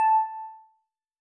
bell
添加三个简单乐器采样包并加载（之后用于替换部分音效）